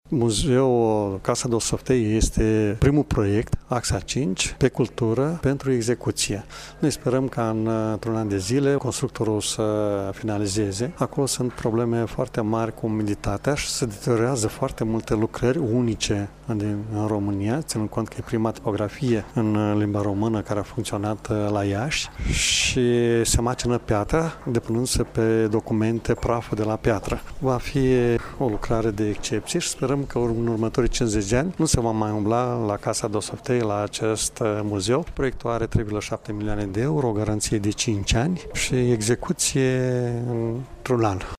Preşedintele administraţiei judeţene, Maricel Popa a precizat că lucrările vor dura maximum 12 luni,  iar firma va asigura garanția  pentru o perioadă de 5 ani de la recepție: